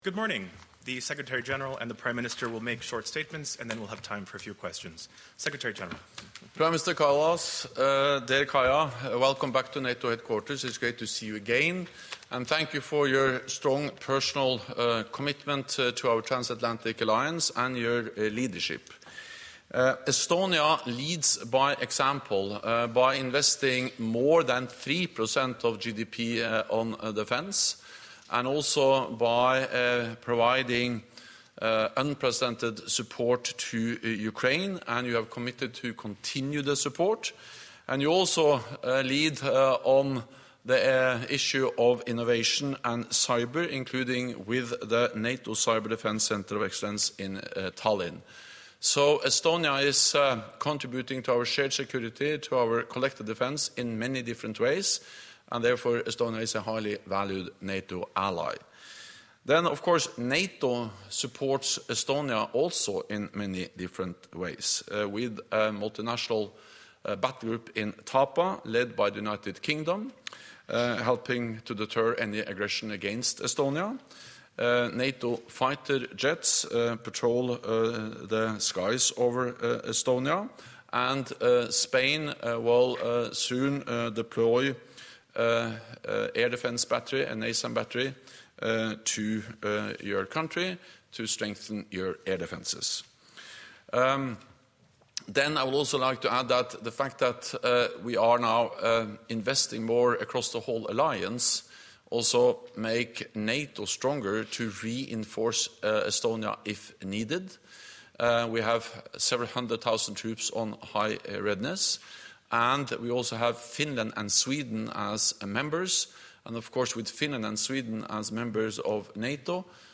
Joint press conference